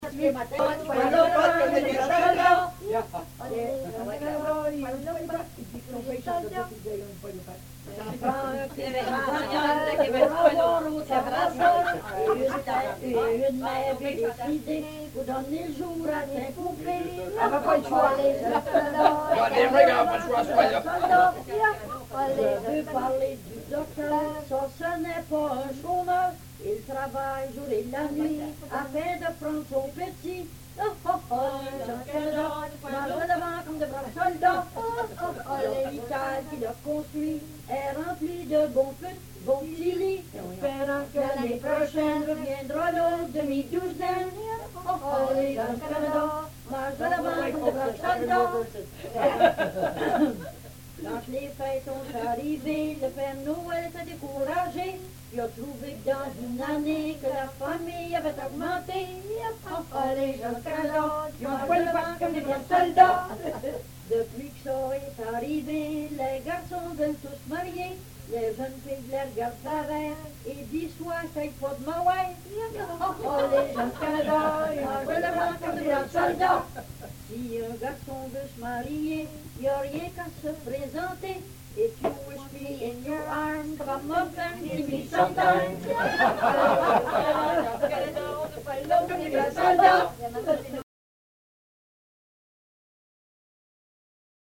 Folk Songs, French--New England Folk Songs, French--Québec (Province)